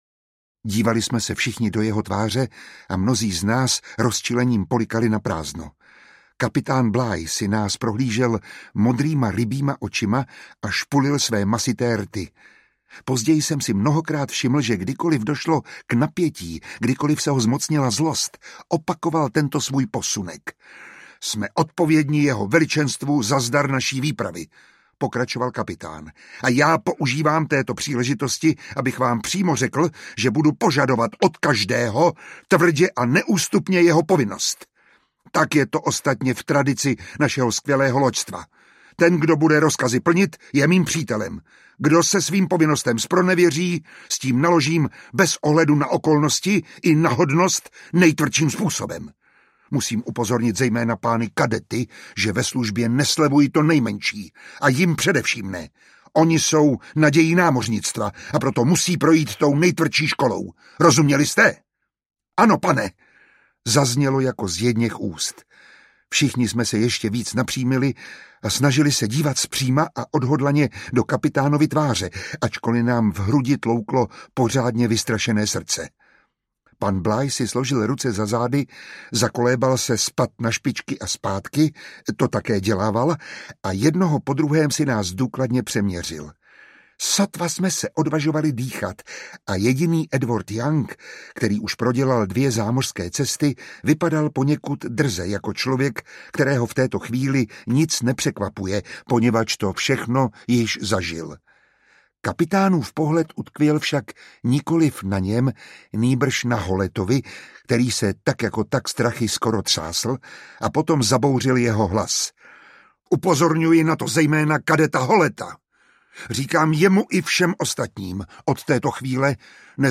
Audiobook
Read: Miroslav Táborský